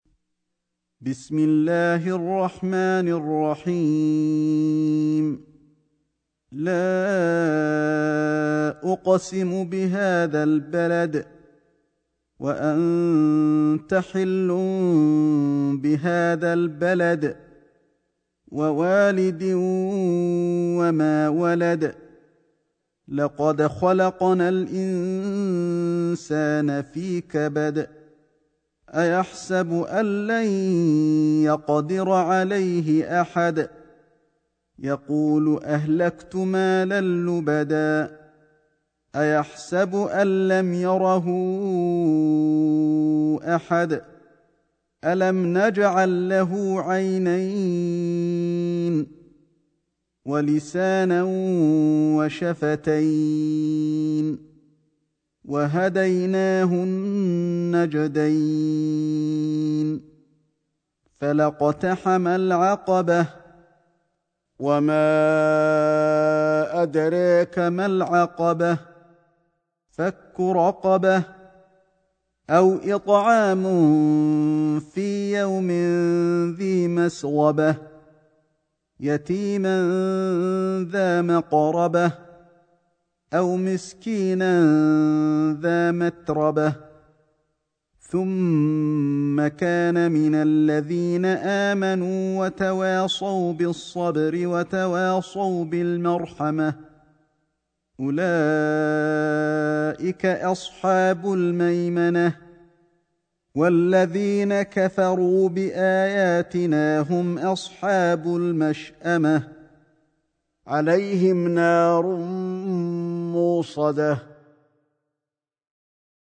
سورة البلد > مصحف الشيخ علي الحذيفي ( رواية شعبة عن عاصم ) > المصحف - تلاوات الحرمين